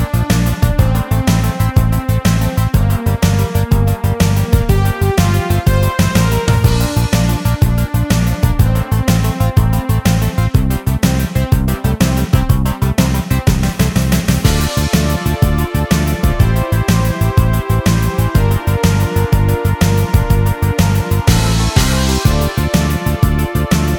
No Bass Or Backing Vocals Pop (1970s) 4:47 Buy £1.50